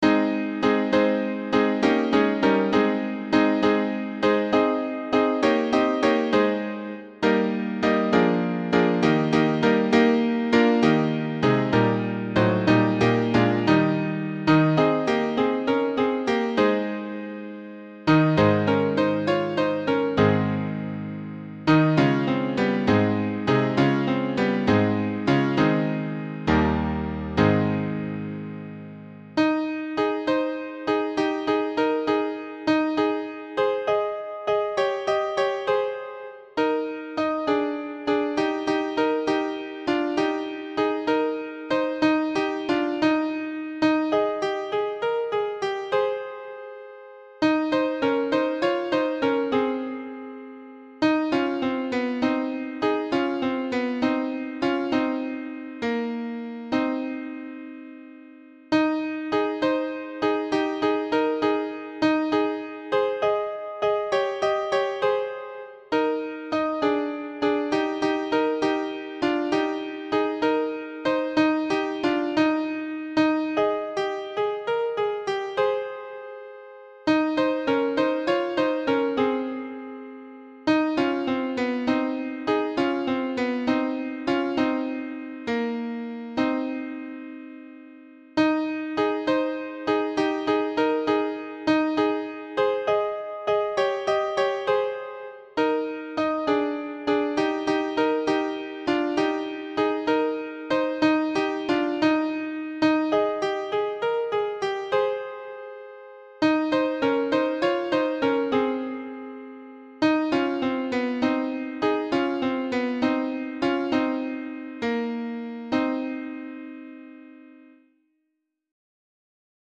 tutti
Le-Noel-des-Bergers-tutti.mp3